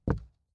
ES_Walk Wood Creaks 20.wav